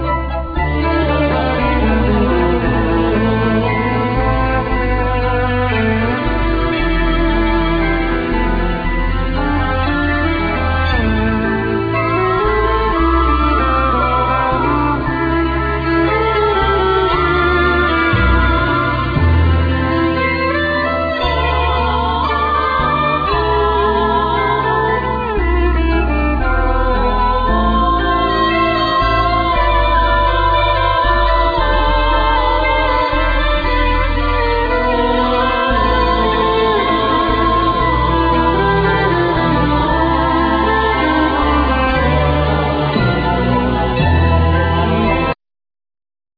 Piano
Guitar,Base,Sequencers